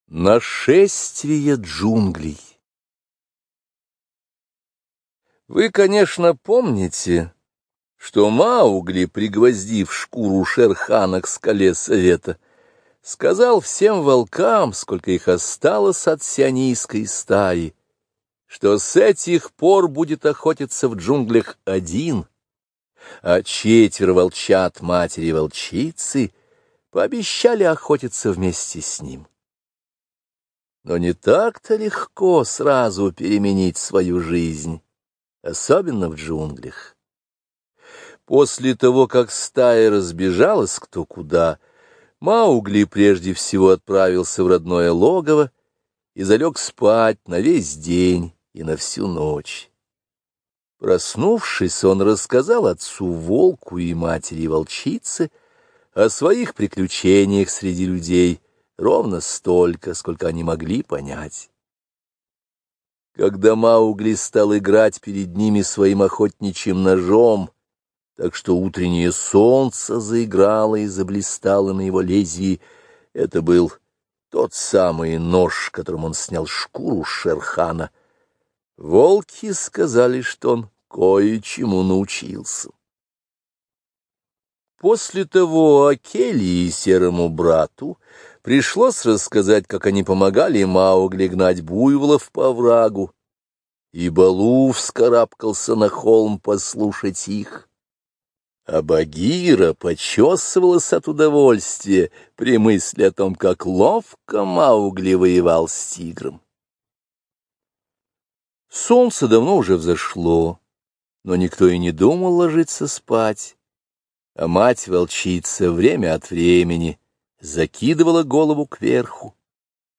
Нашествие джунглей — слушать аудиосказку Редьярд Киплинг бесплатно онлайн